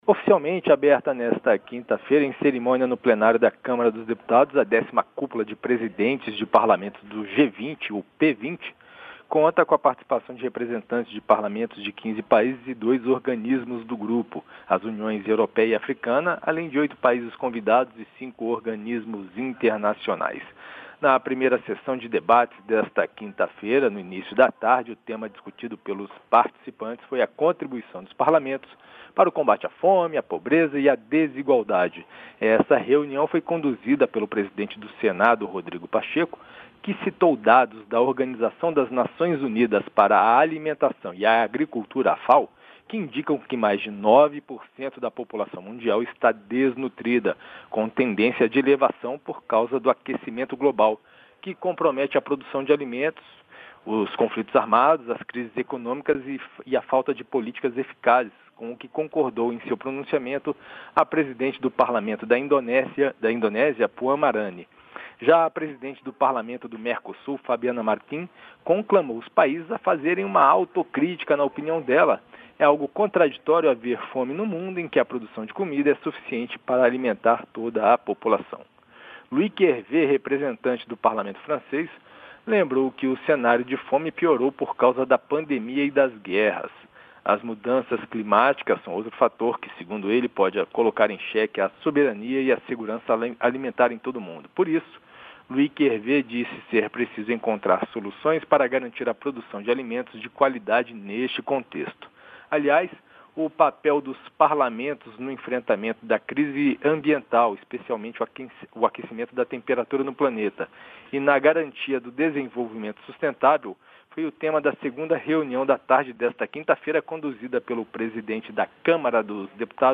Resumo do dia